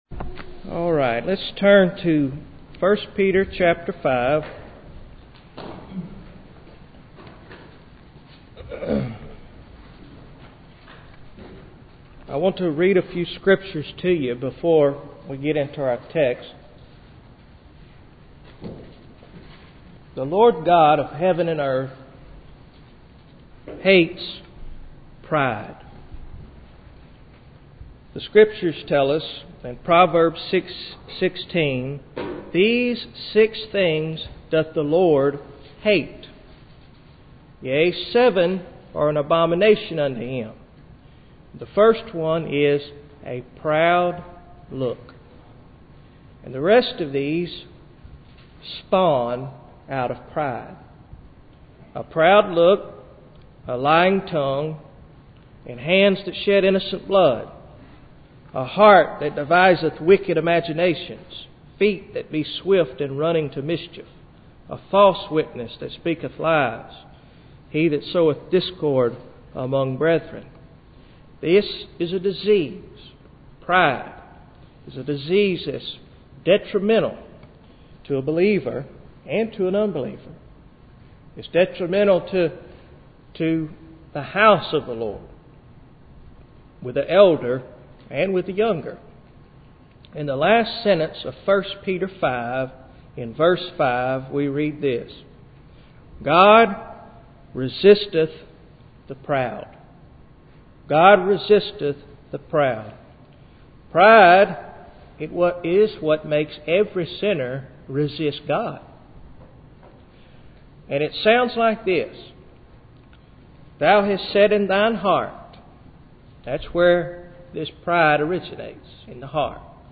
Date 22-Aug-2010 Article Type Sermon Notes PDF Format pdf Word Format doc Audio HI-FI Listen: The Mighty Hand of God (32 kbps) Audio CD Quality Listen: The Mighty Hand of God (128 kbps) Length 51 min.